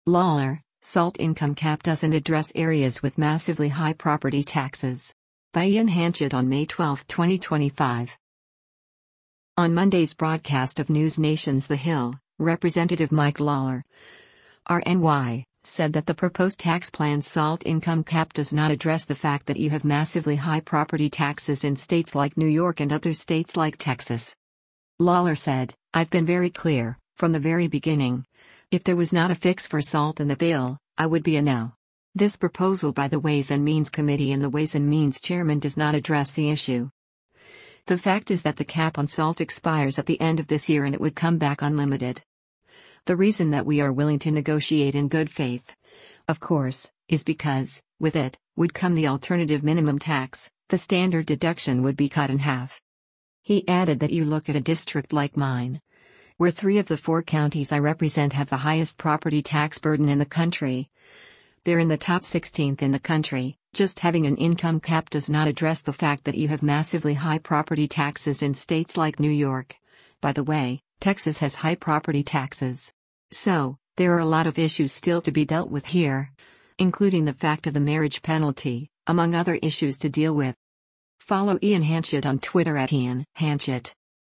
On Monday’s broadcast of NewsNation’s “The Hill,” Rep. Mike Lawler (R-NY) said that the proposed tax plan’s SALT income cap “does not address the fact that you have massively high property taxes in states like New York” and other states like Texas.